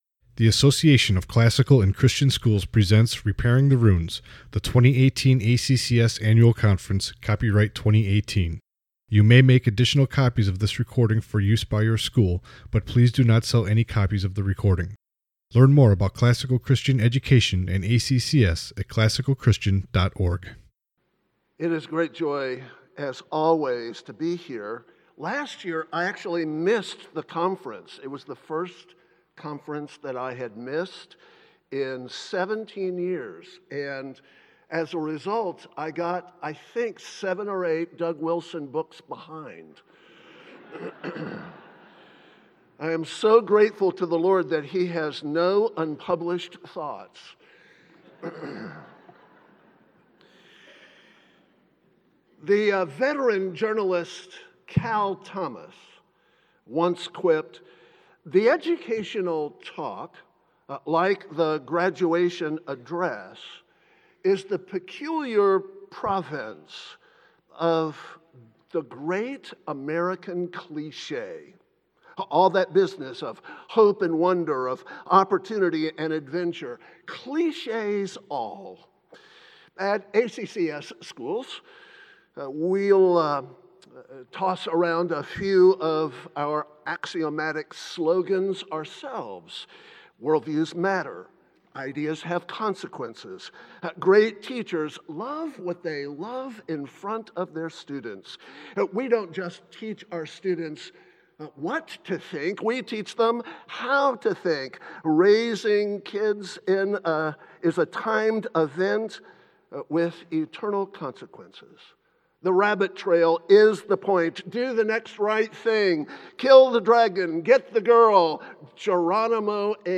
2018 Plenary Talk | 44:30 | All Grade Levels, General Classroom